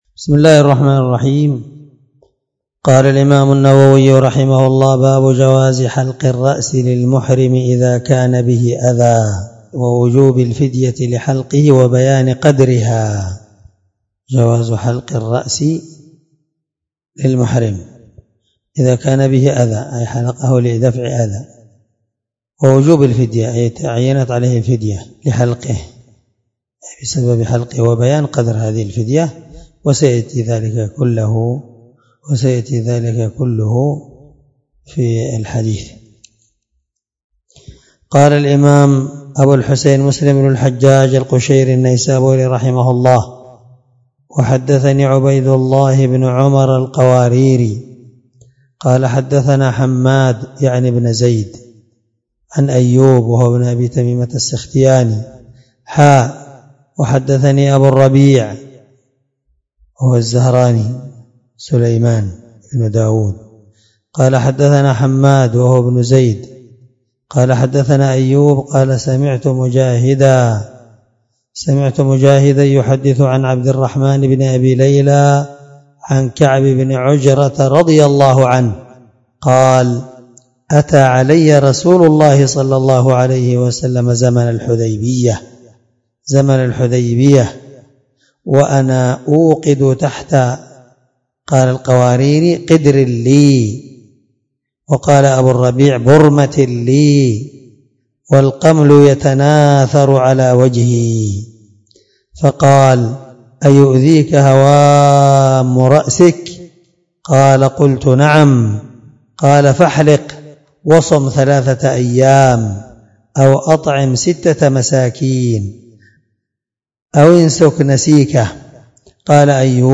الدرس 10من شرح كتاب الحج حديث رقم(1201) من صحيح مسلم